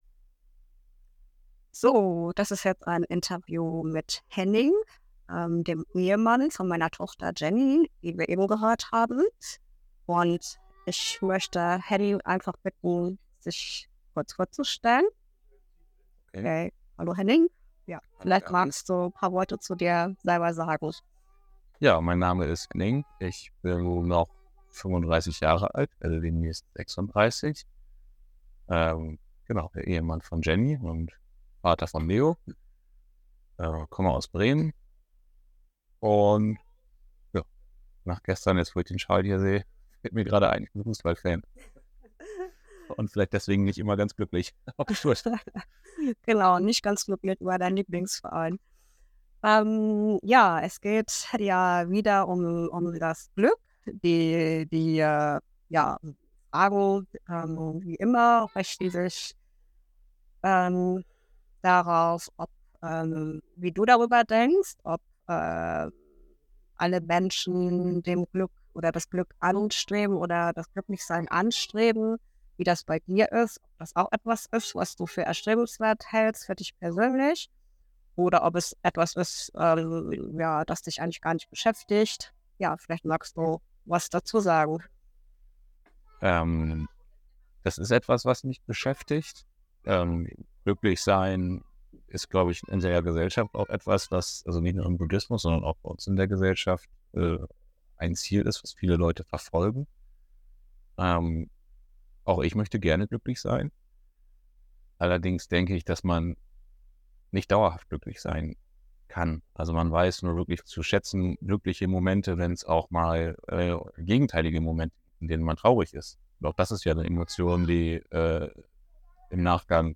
Interviewreihe zum Thema Glück